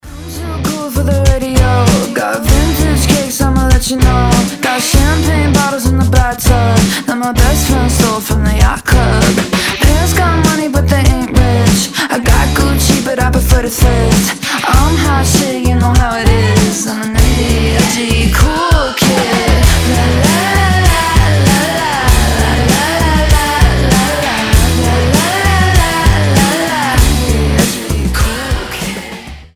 • Качество: 320, Stereo
Pop Rock
озорные
поп-панк